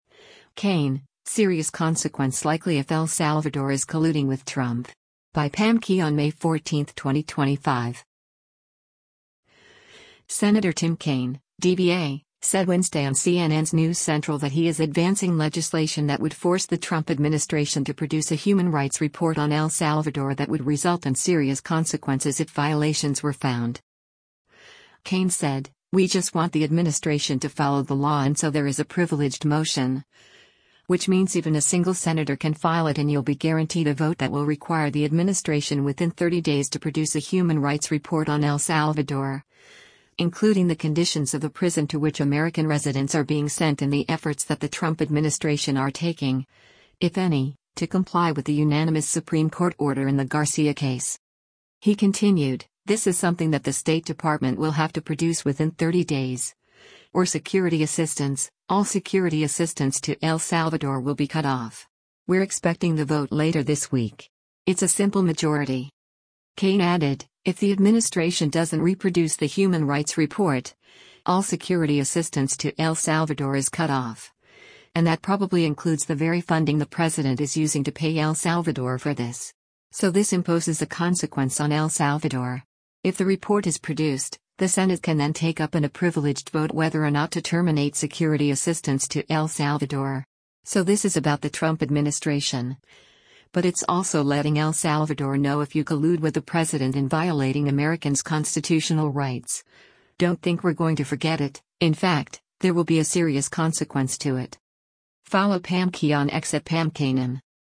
Senator Tim Kaine (D-VA) said Wednesday on CNN’s “News Central” that he is advancing legislation that would force the Trump administration to produce a human rights report on El Salvador that would result in “serious consequences” if violations were found.